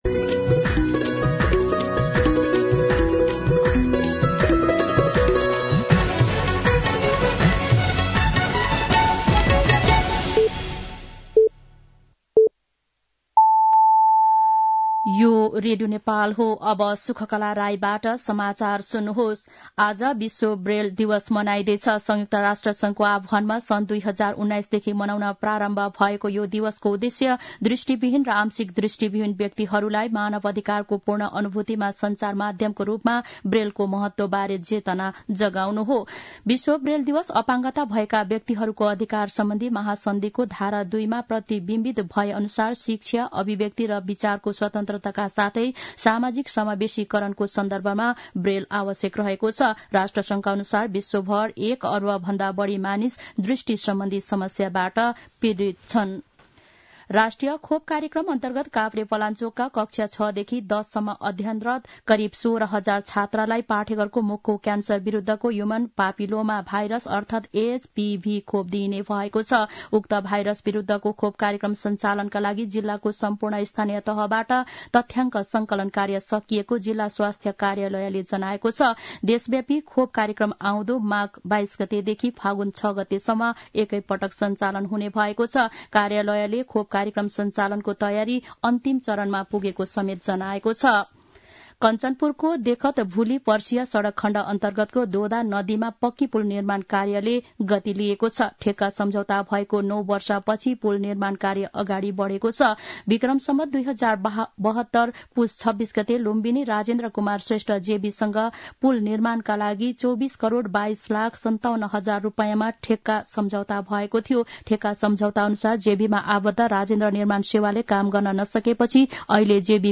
मध्यान्ह १२ बजेको नेपाली समाचार : २१ पुष , २०८१
12-pm-Nepali-News.mp3